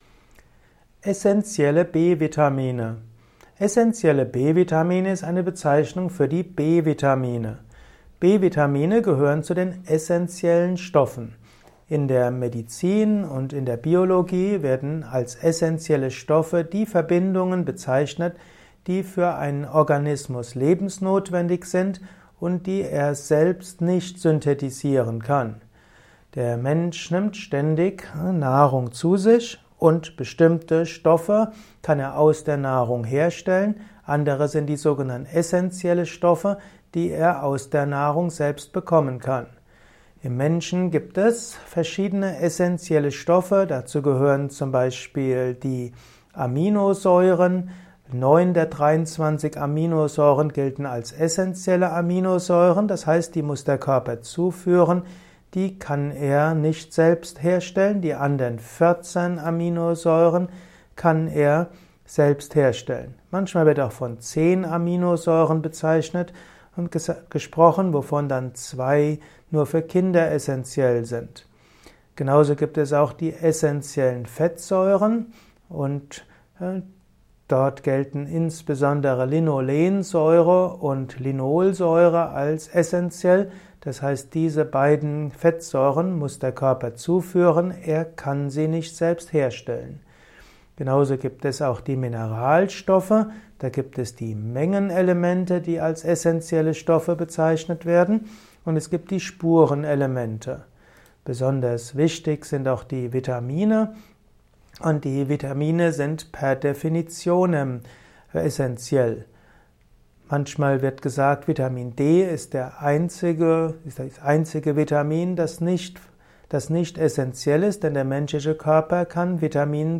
Ein Kurzvortrag über Essentielle B-Vitamine